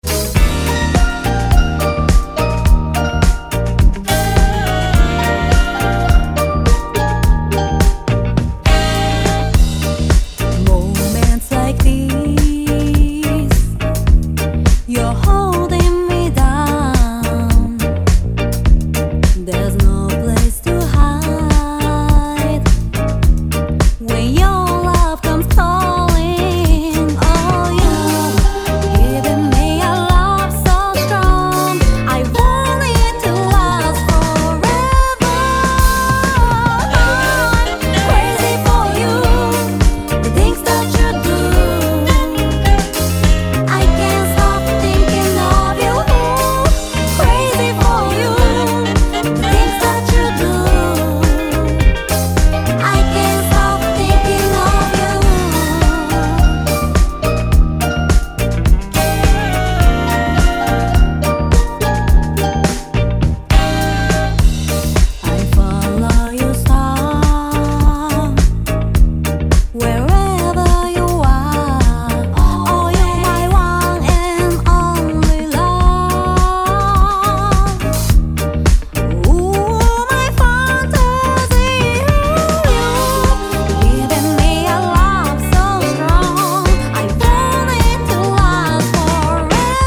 REGGAE / SKA / DUB